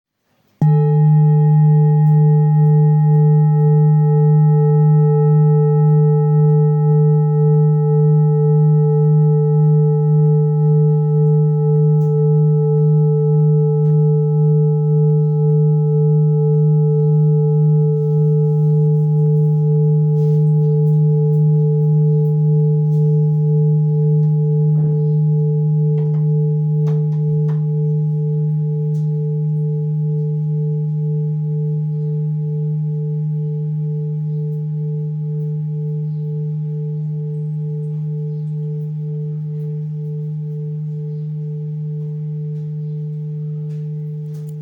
Full Moon Bowl, Buddhist Hand Beaten, Moon Carved, Antique Finishing
Material Seven Bronze Metal
This is a Himalayas handmade full moon singing bowl. The full moon bowl is used in meditation for healing and relaxation sound therapy.